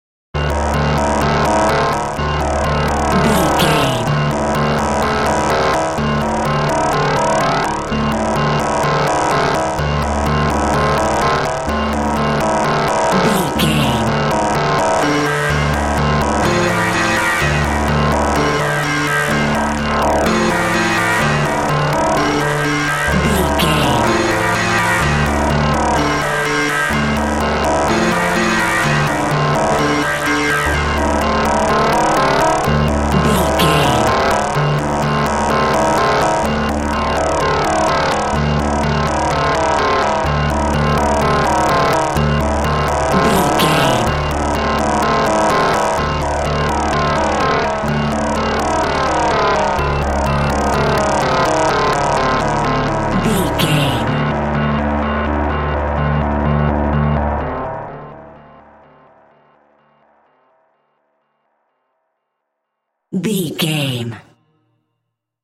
In-crescendo
Thriller
Aeolian/Minor
scary
tension
ominous
dark
eerie
Horror synth
Horror Ambience
electronics
synthesizer